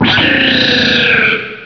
pokeemerald / sound / direct_sound_samples / cries / cobalion.aif
-Reintroduced the Gen. 4 and 5 cries.